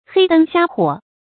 黑燈瞎火 注音： ㄏㄟ ㄉㄥ ㄒㄧㄚ ㄏㄨㄛˇ 讀音讀法： 意思解釋： 形容黑暗沒有燈光。